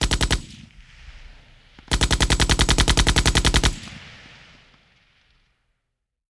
Machine Gun (RUN).wav